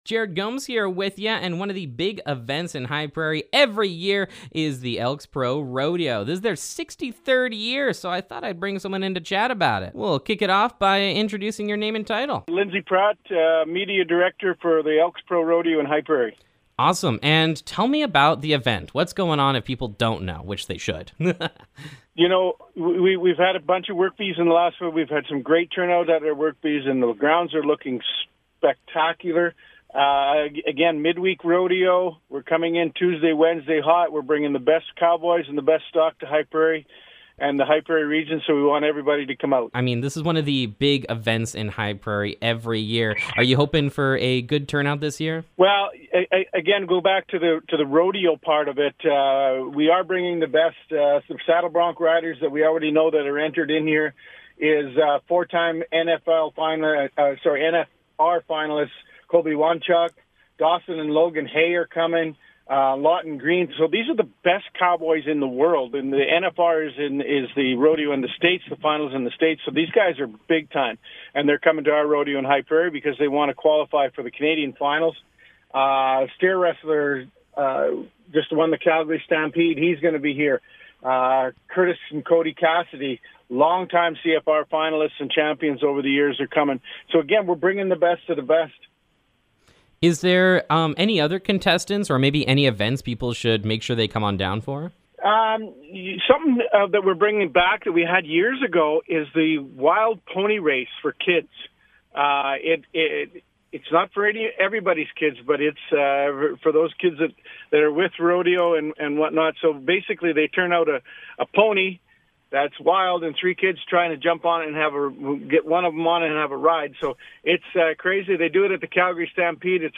HP Elks Pro Rodeo Interview
hp-rodeo-interview-2025.mp3